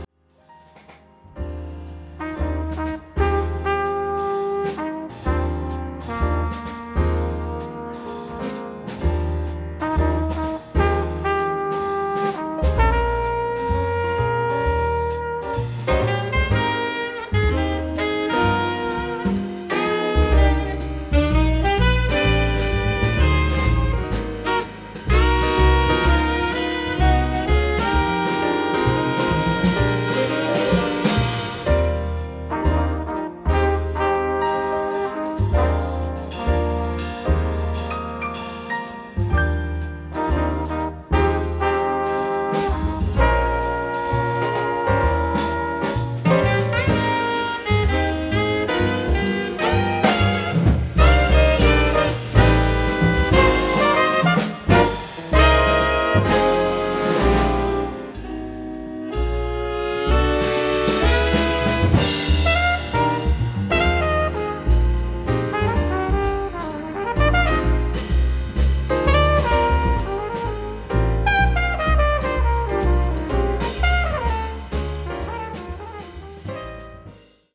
響きあう五つの個性、華ひらく豊かなハーモニー。 柔らかな旋律に彩られた、物語性に溢れる世界を描く楽曲。
響きあいの中から、豊かな、そして新たなハーモニーが華ひらいてゆく。